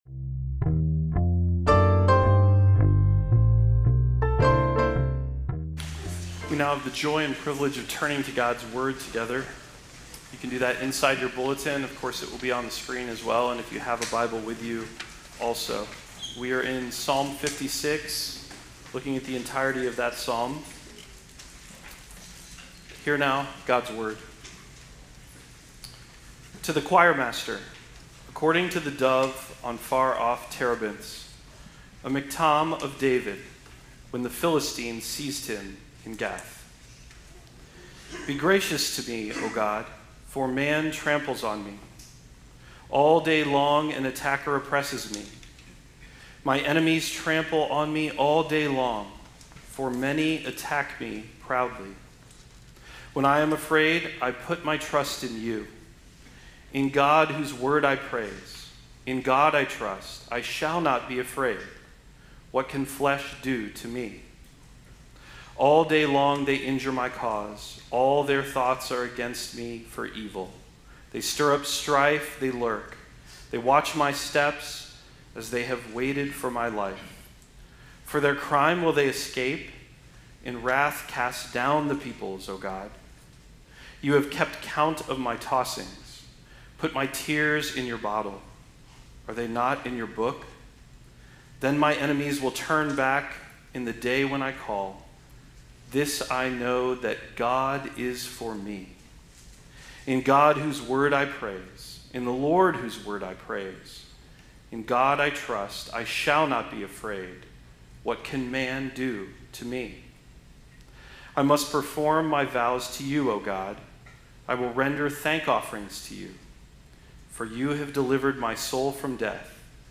Psalm 56 Service Type: Sunday Worship Human conflict and relational friction are part of life.